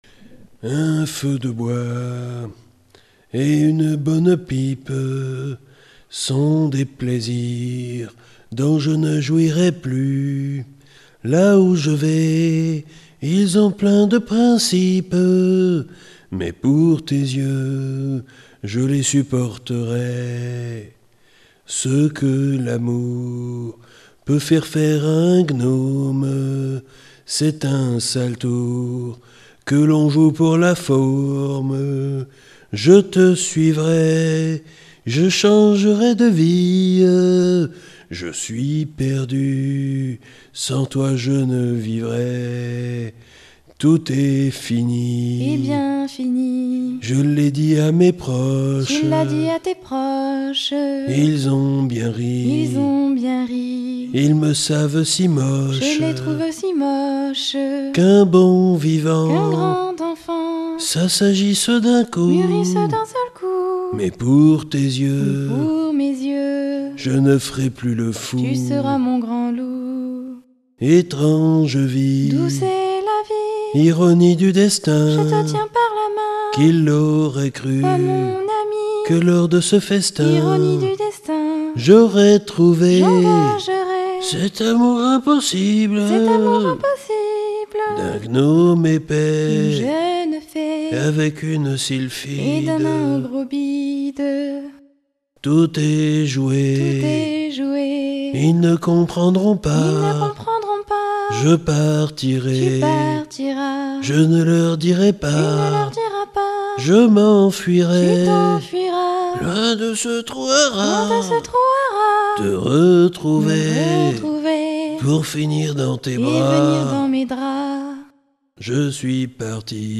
chant de gnome.